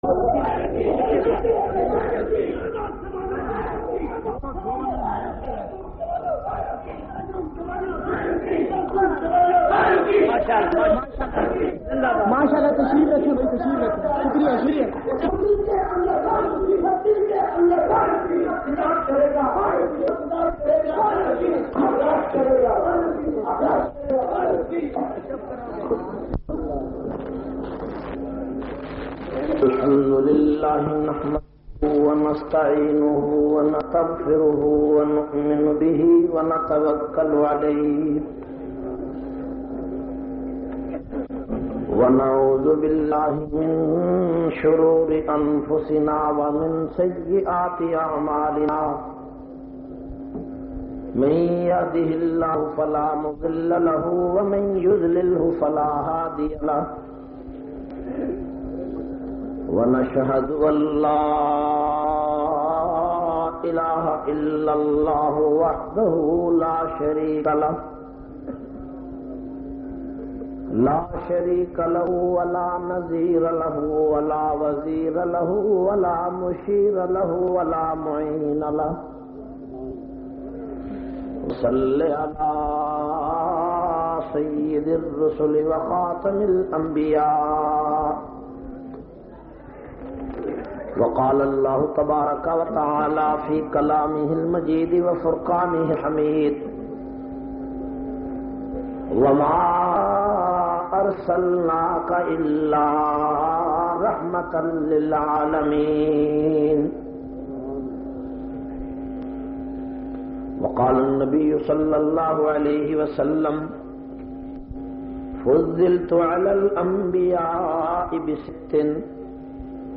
383- Khatam ul Masomeen Conference Chowk Singlan wala Khaniwal.mp3